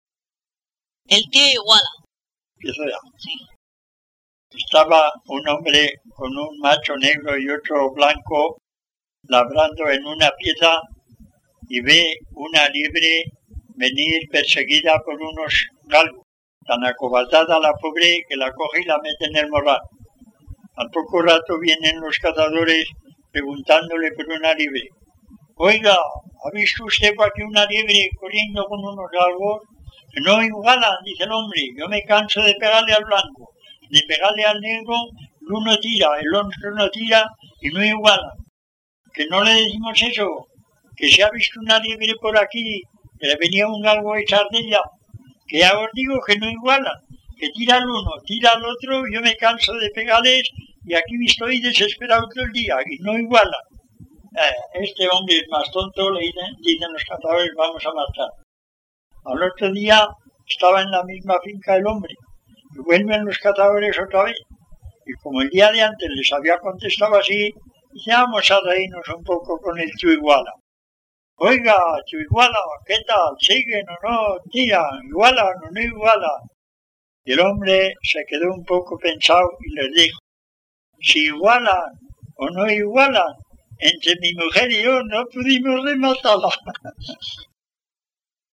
Clasificación: Cuentos
Lugar y fecha de recogida: Calahorra, año 2001